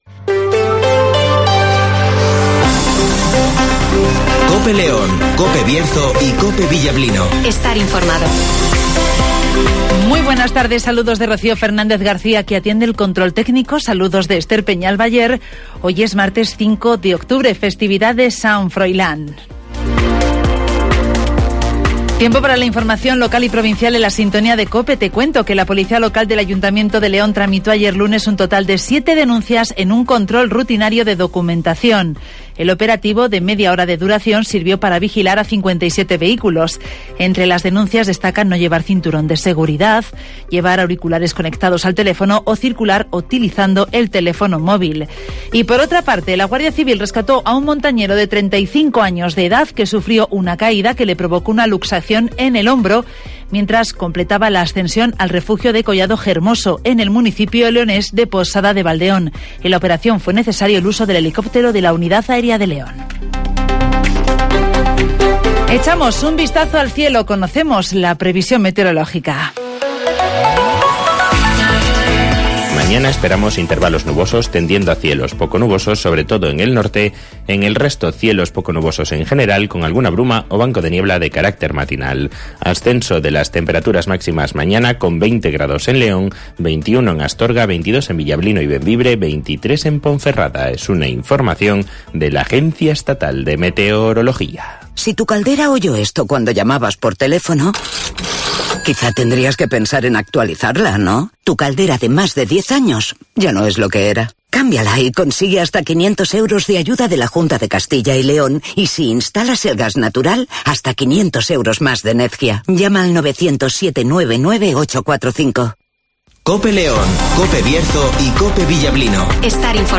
AUDIO: Repaso a la actualidad informativa de la provincia de León. Escucha aquí las noticias con las voces de los protagonistas.